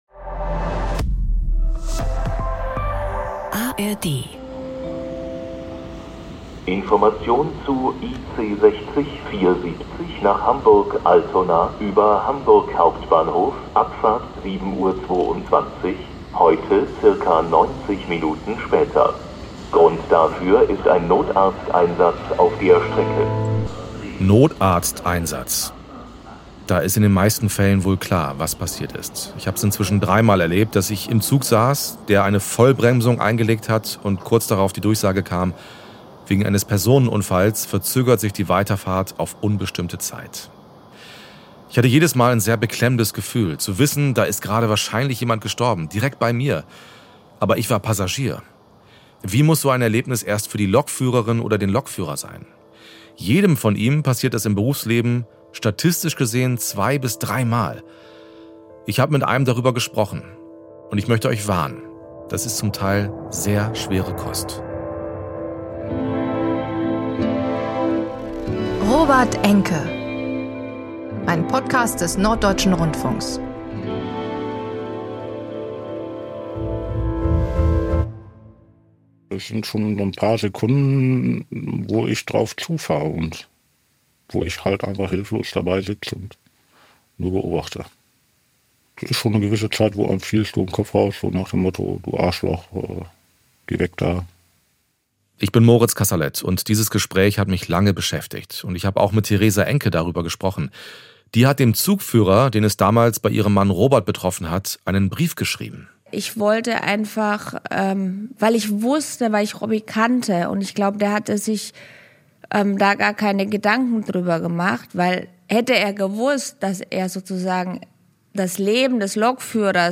Dabei passiert es jedem Lokführer zwei bis dreimal, dass sich ein Mensch vor ihren Augen das Leben nimmt. Ein Betroffener spricht darüber.